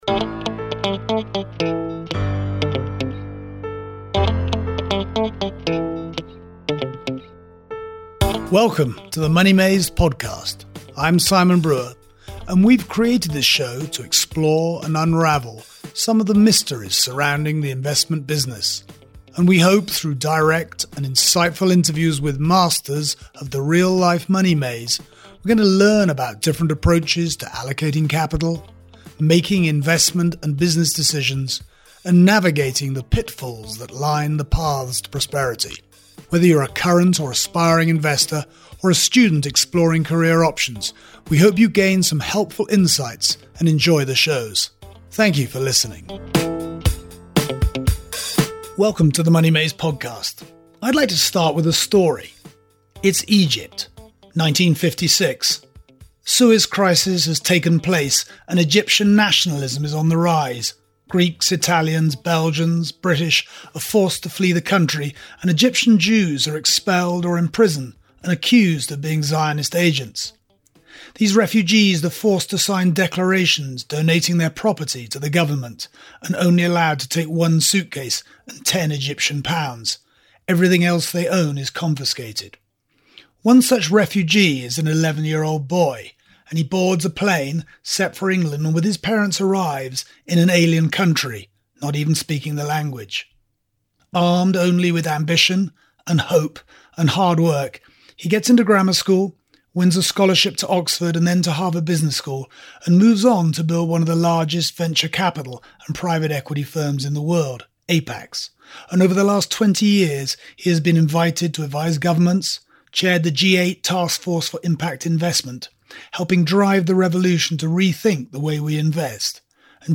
[REPLAY] - Sir Ronald Cohen: From Venture Capital and Private Equity to Impact investing, A conversation with the man who leads the charge!